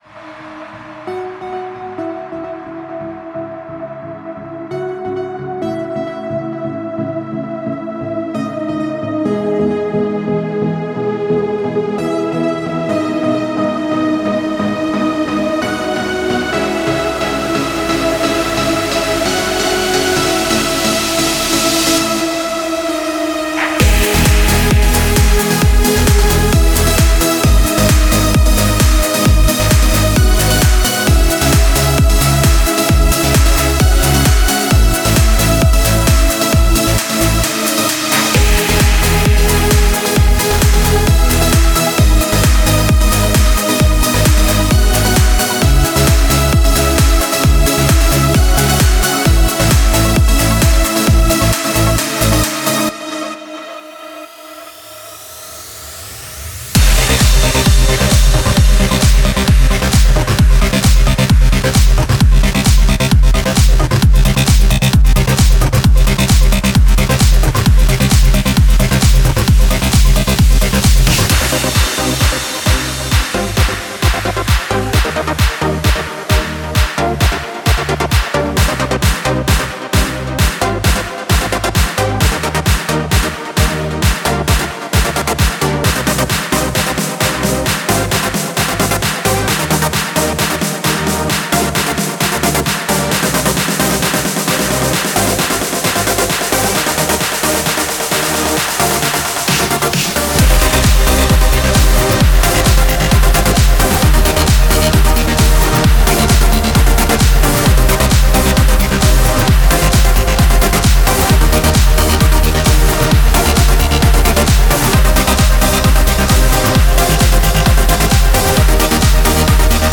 • Качество: 128, Stereo
громкие
без слов
нарастающие
progressive trance
клубная музыка